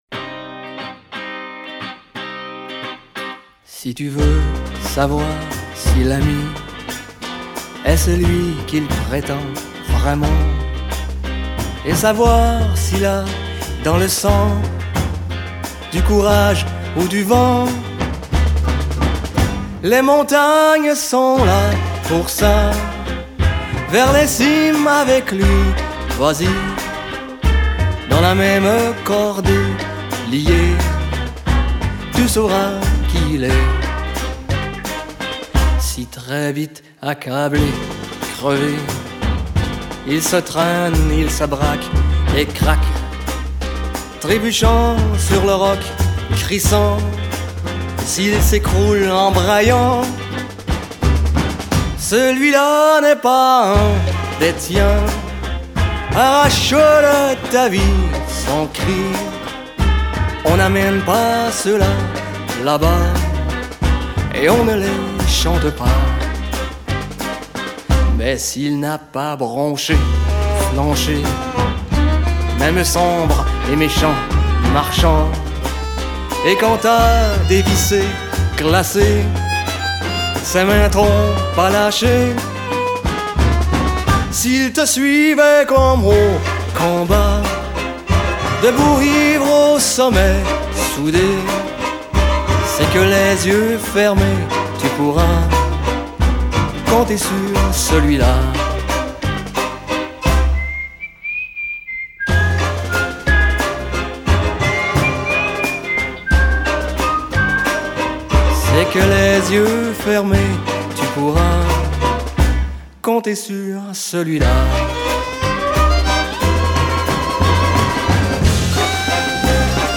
контрабас
аккордеон и скрипка
фортепиано